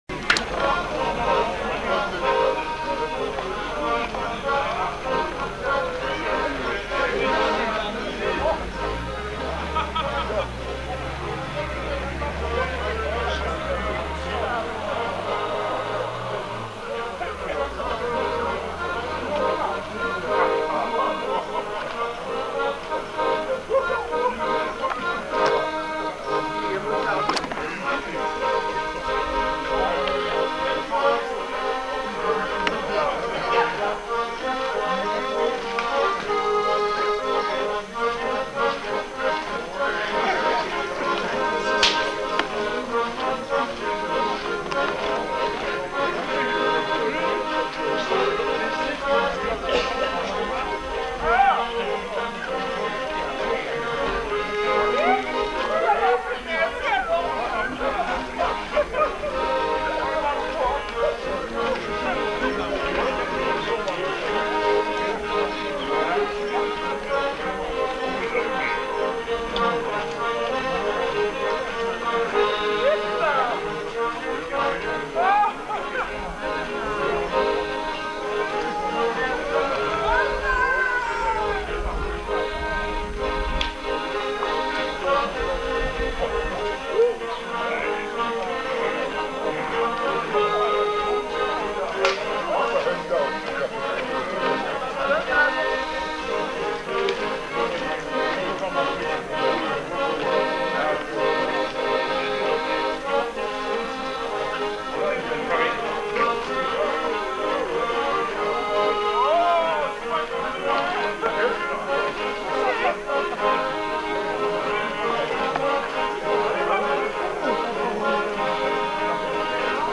Aire culturelle : Savès
Lieu : Espaon
Genre : morceau instrumental
Instrument de musique : accordéon diatonique
Danse : youska